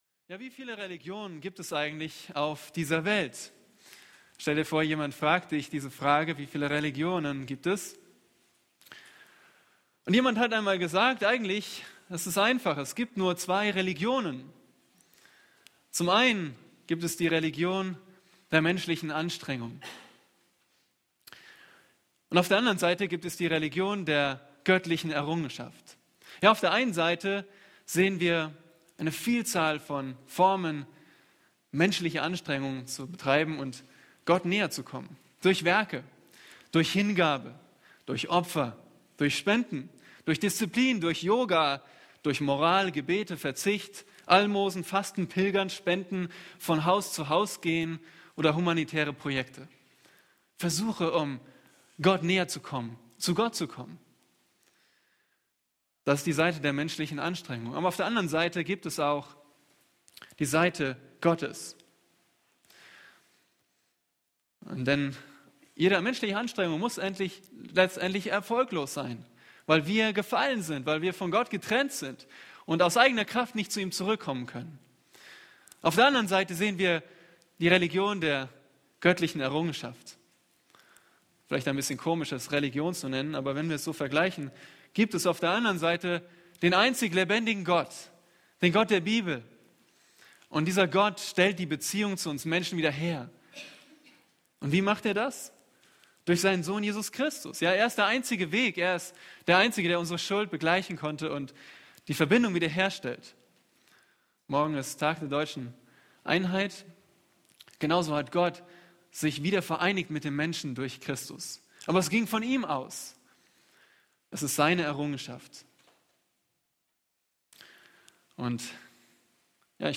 A predigt from the serie "Systematische Theologie."